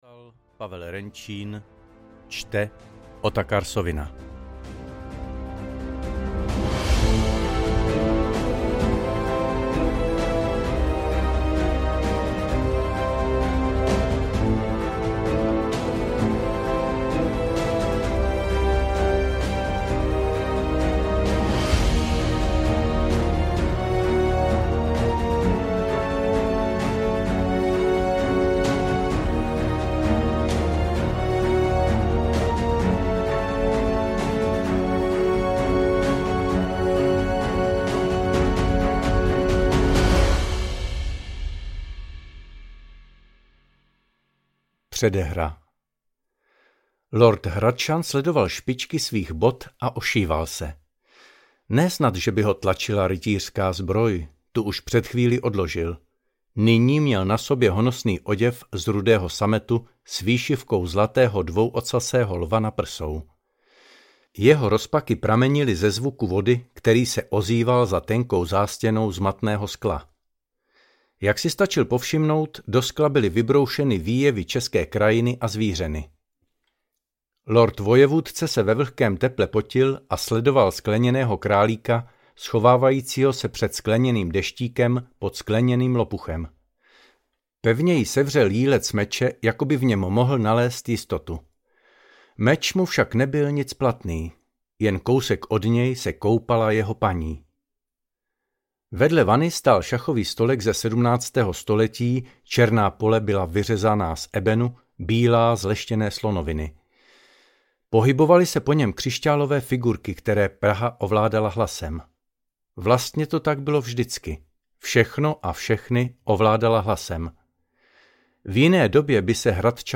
Runový meč audiokniha
Ukázka z knihy